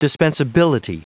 Prononciation du mot dispensability en anglais (fichier audio)
Prononciation du mot : dispensability